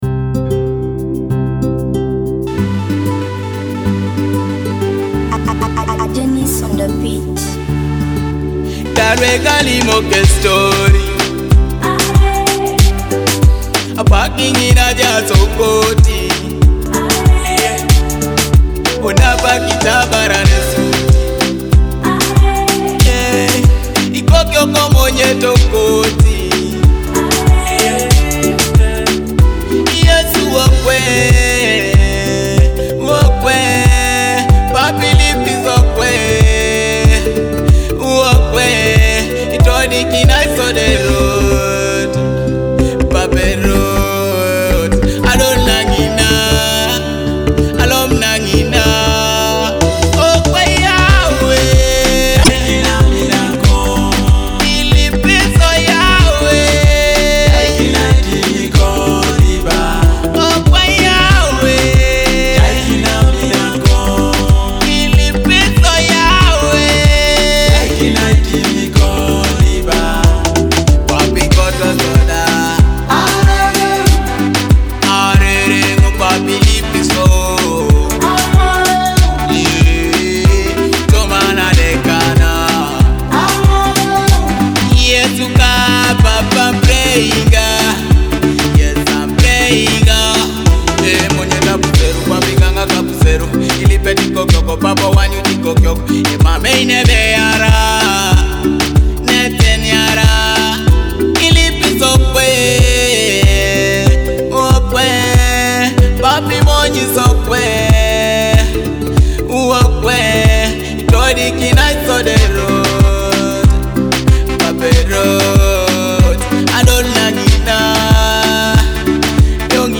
vibrant and powerful Teso gospel track
With heartfelt lyrics and infectious rhythms